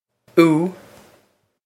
ú oo
This is an approximate phonetic pronunciation of the phrase.